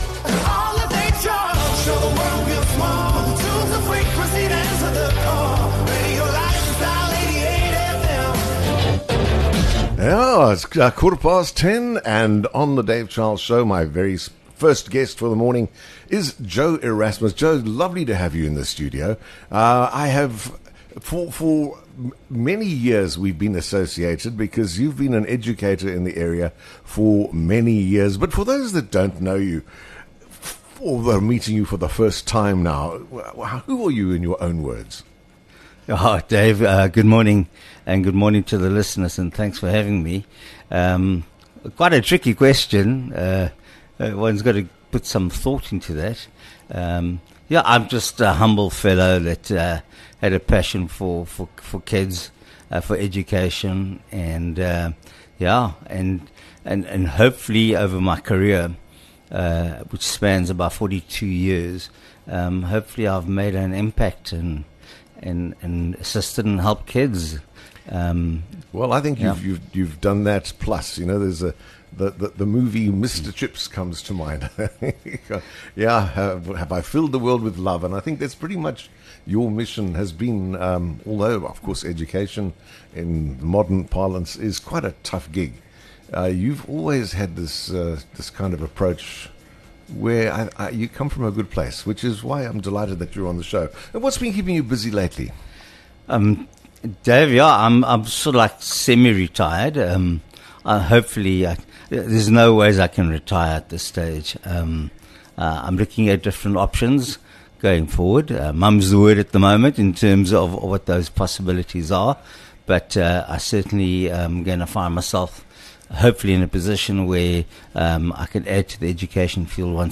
Whether it’s your favourite songs, meaningful milestones, or the moments that shaped you, come and share them live on air.